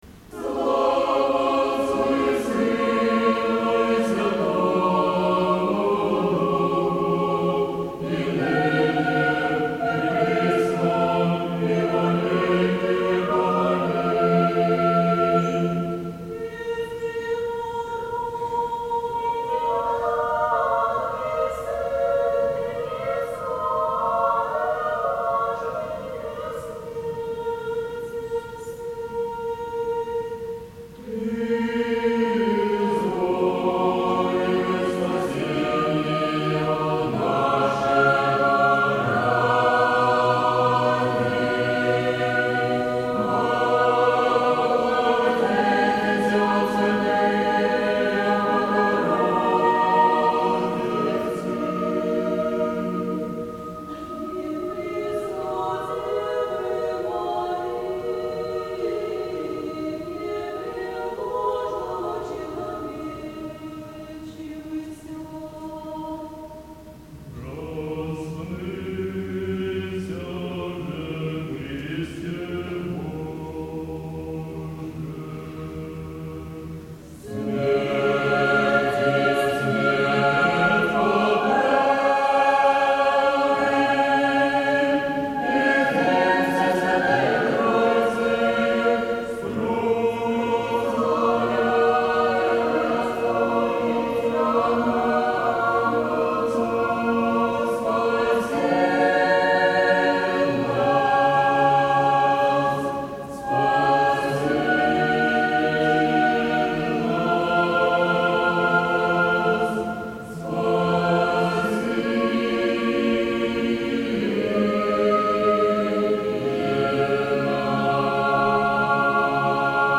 Запись с фестиваля
церковно-приходских хоров, г. Владимир, 2006 год.